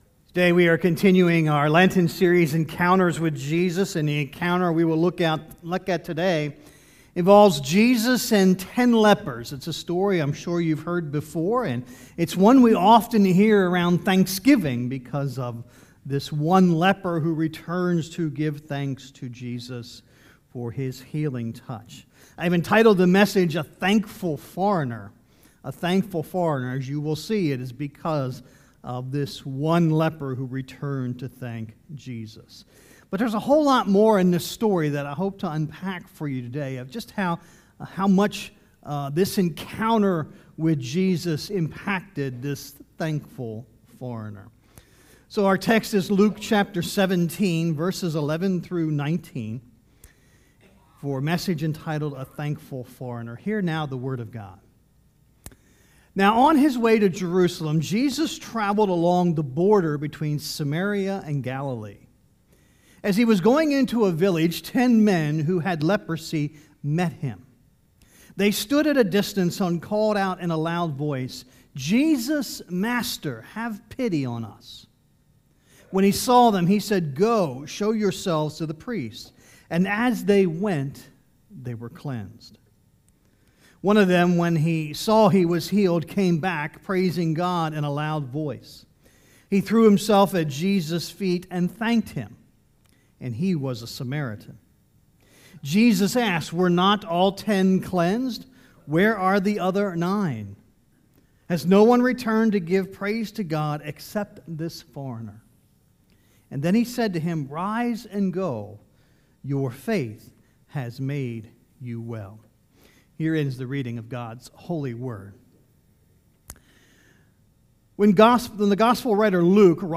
Sermons | Mechanic Grove Church of the Brethren